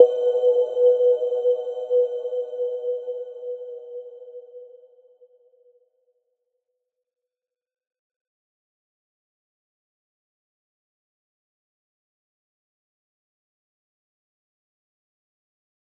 Little-Pluck-B4-f.wav